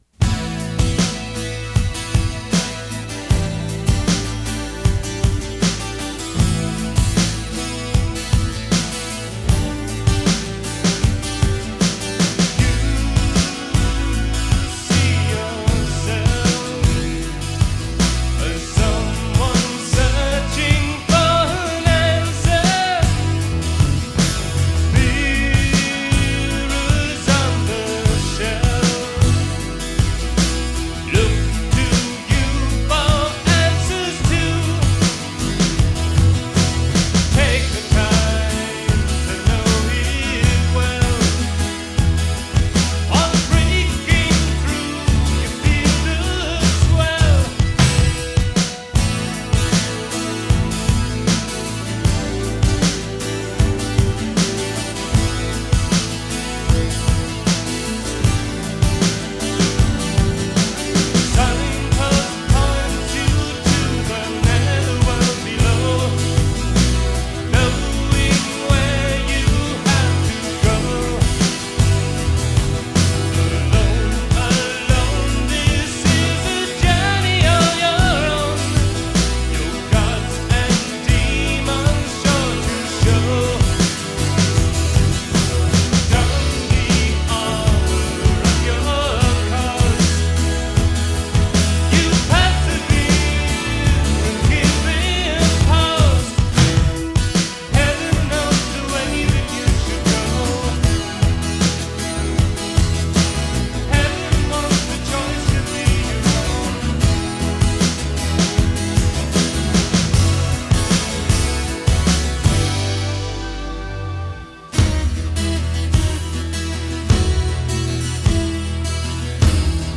A modestly ambitious (at least, for this amateur musician and would-be recording studio engineer) 4-track home recording of some pieces quite on my mind at the time. The master tape had deteriorated somewhat over the years prior to its eventual transfer to digital media in the mid-2000s.